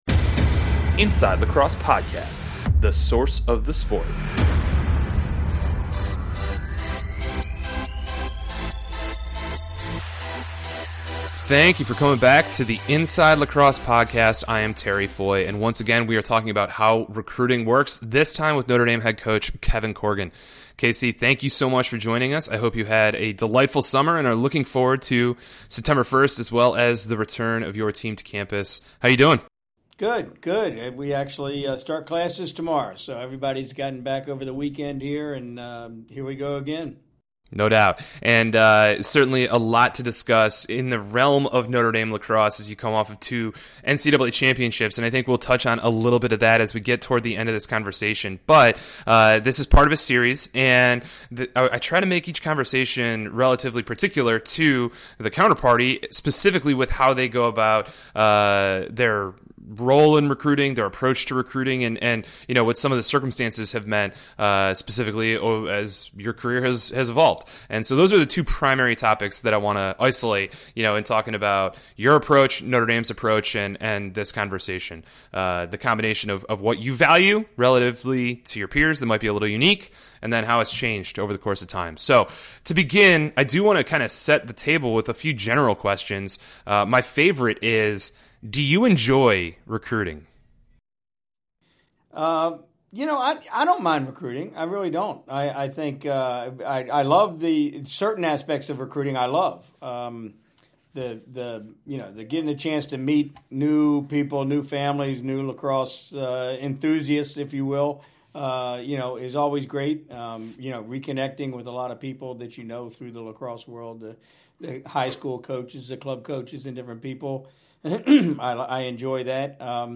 he's interviewing a series of DI men's lacrosse coaches about their process